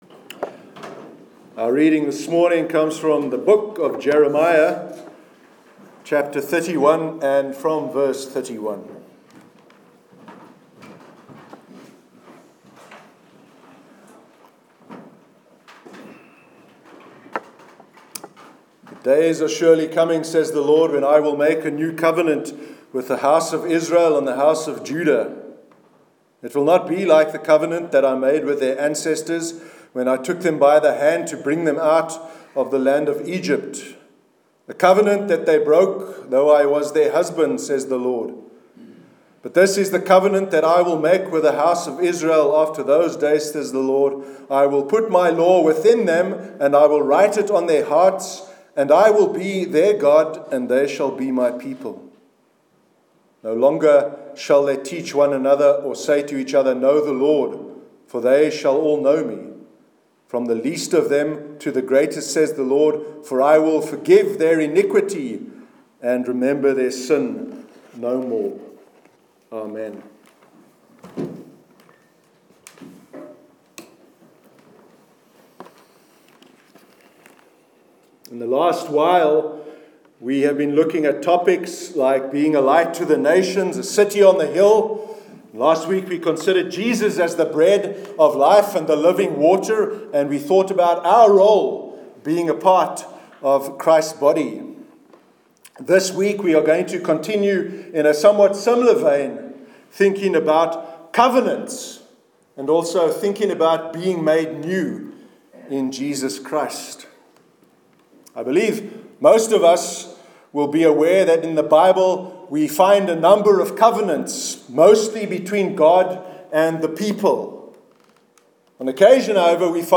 Sermon on God’s Covenants- 19th Feb 2017